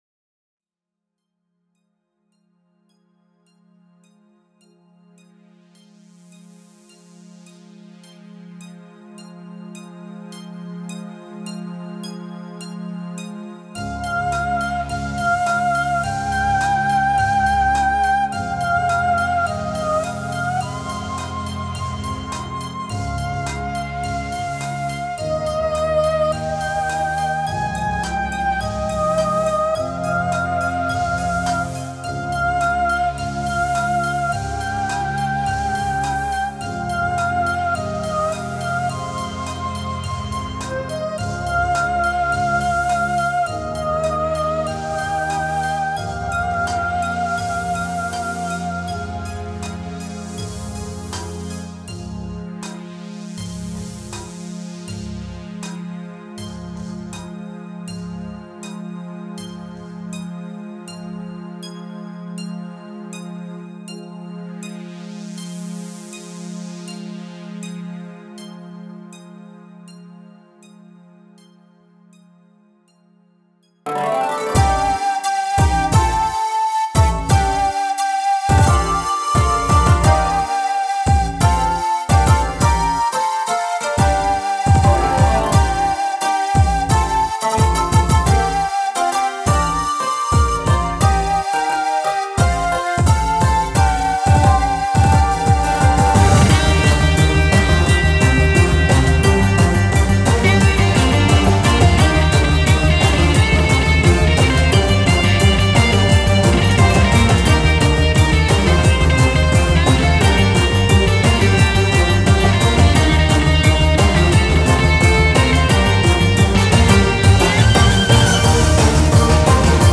Genre: Indie.